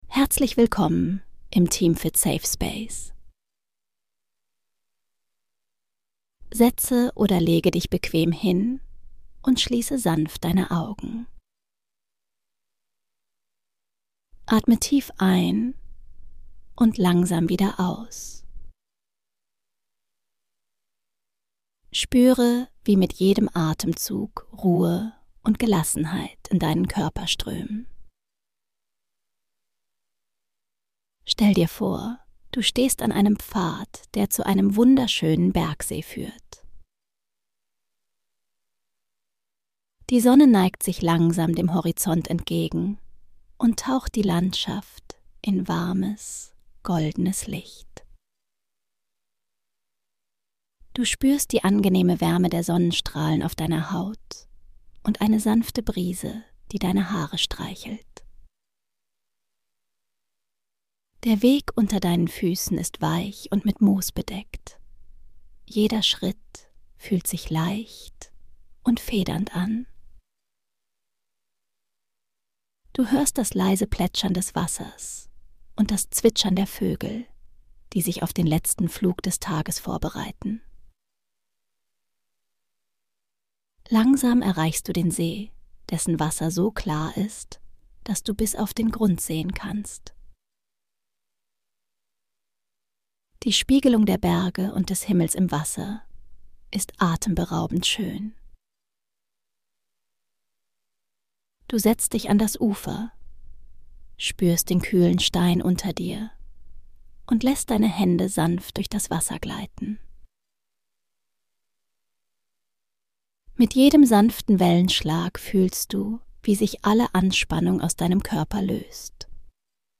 Eine geführte Traumreise zu einem friedlichen Bergsee bei